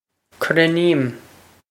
cruinním krin-eem
krin-eem
This is an approximate phonetic pronunciation of the phrase.